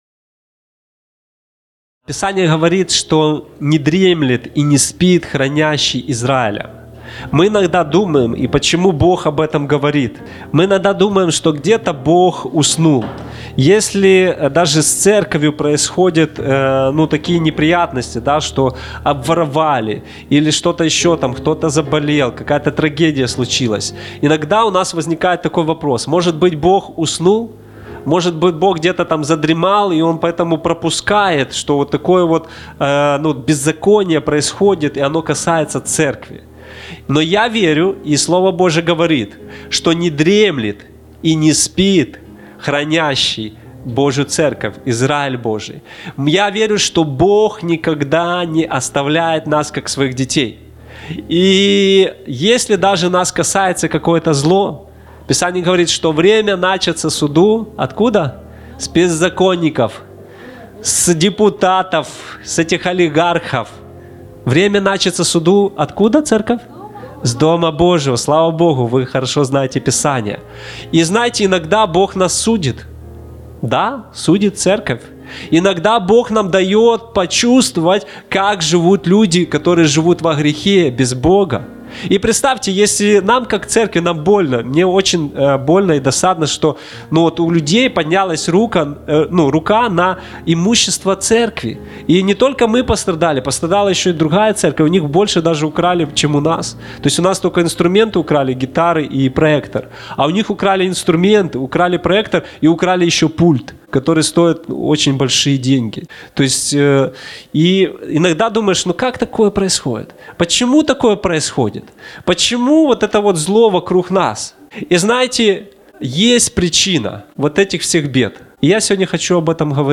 Актуальна проповідь